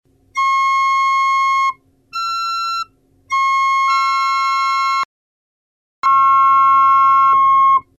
Si vous n'entendez pas bien la résultante, la même chose mais, en filtrant les fréquences, j'ai renforcé la résultante (après 1 seconde de silence):